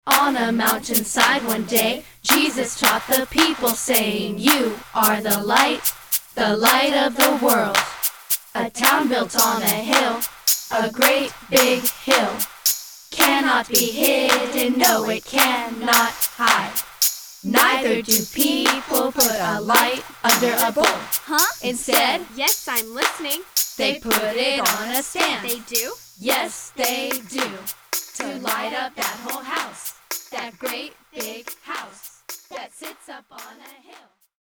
and games for children's choirs.